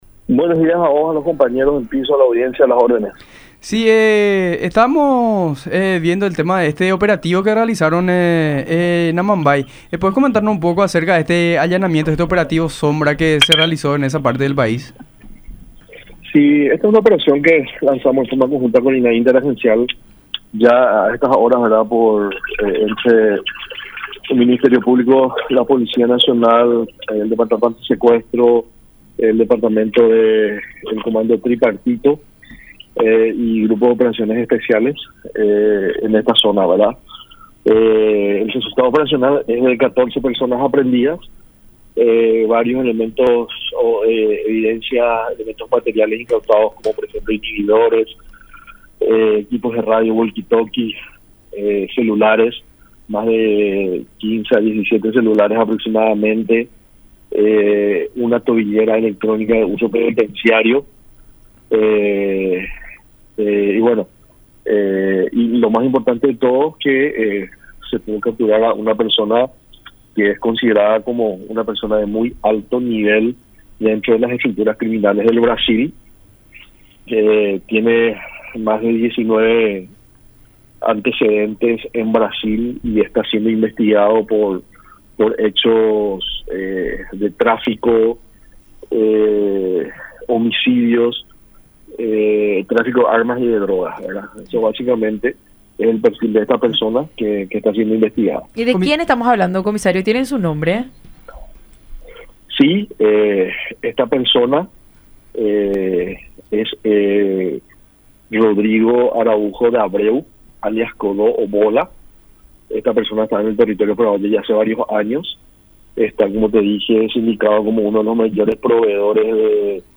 “Sería uno de los más grandes proveedores de marihuana al Comando Vermelho. Estaba en el país con cédula paraguaya pero con un nombre falso”, dijo el comisario Nimio Cardozo, jefe del Departamento Antisecuestros de la Policía Nacional, en diálogo con La Mañana De Unión a través de Unión TV y radio La Unión.